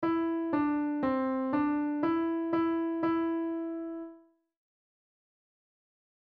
On the piano, play Mary Had A Little Lamb
E D C D E E E